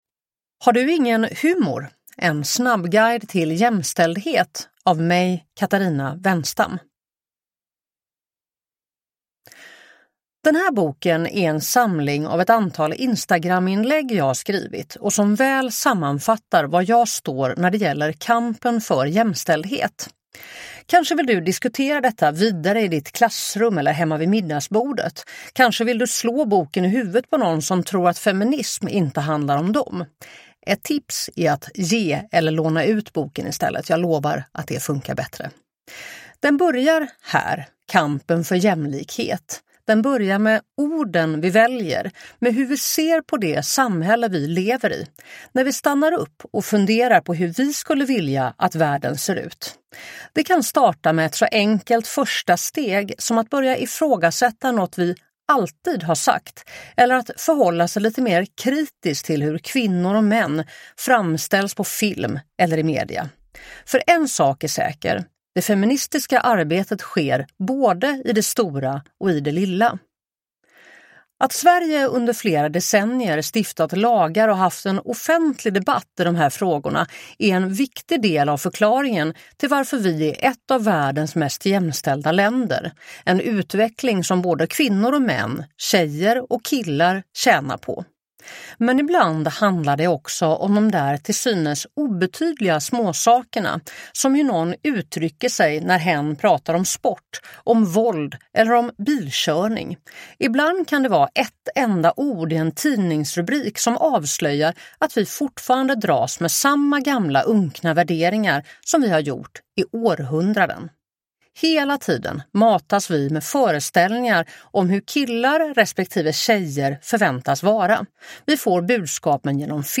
Uppläsare: Katarina Wennstam